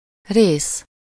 Ääntäminen
Synonyymit party faction position shed role element (brittienglanti) parting component chelek portion section function depart installment Ääntäminen US : IPA : /pɑɹt/ UK : IPA : /pɑːt/ AU : IPA : /pɑːt/ Lyhenteet ja supistumat (laki) pt. pt